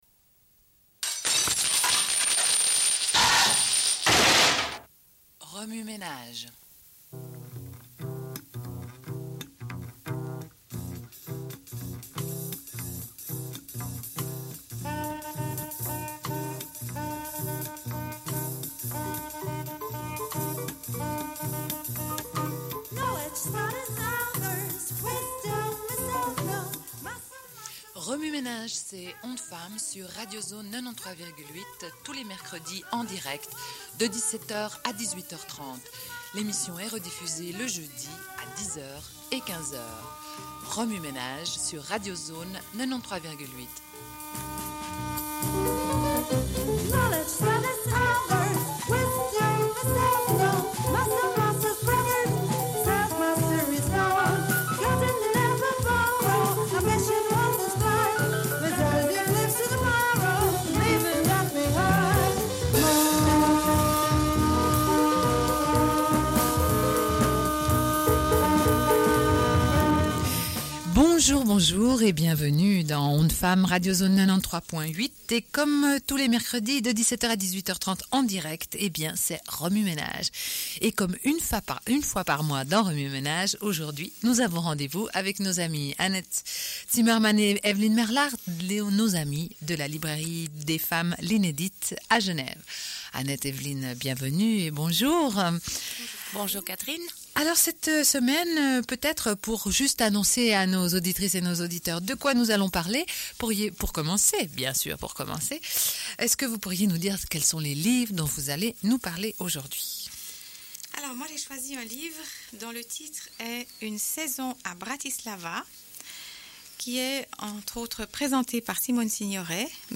Une cassette audio, face A00:31:16